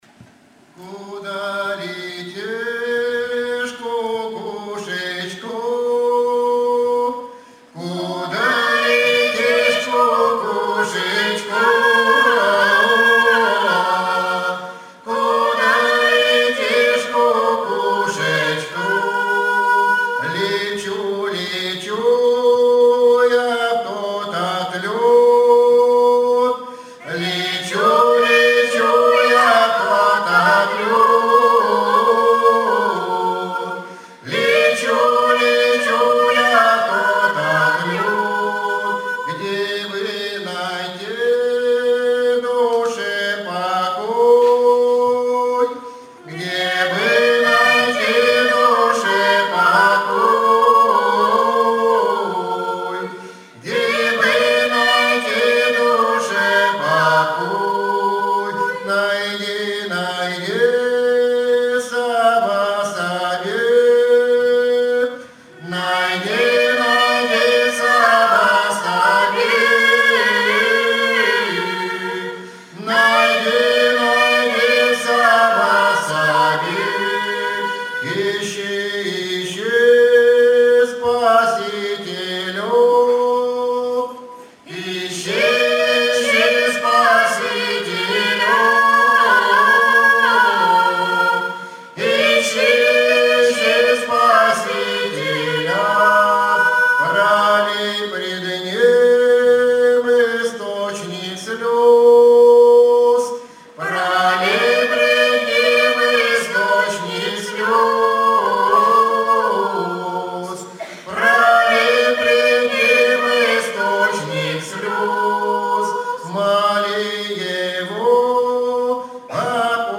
Традиционная